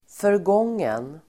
Uttal: [förg'ång:en]